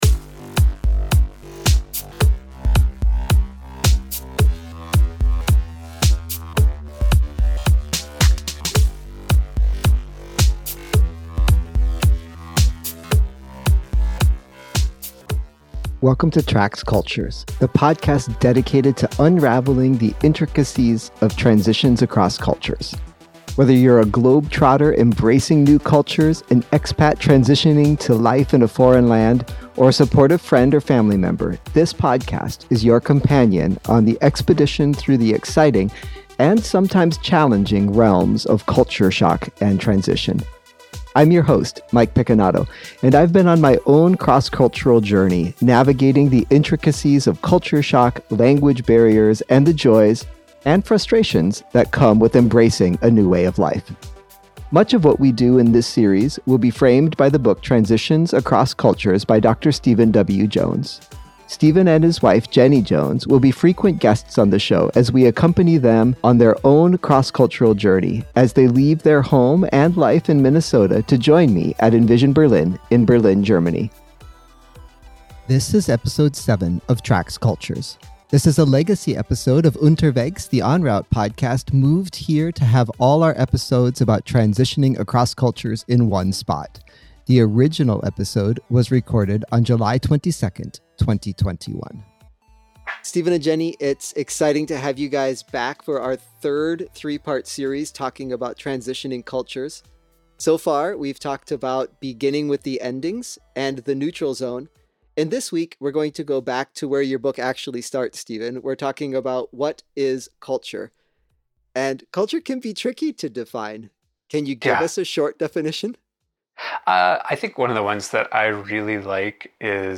In this conversation, they start by discussing the elusive nature of culture and the various definitions that have been proposed over the years.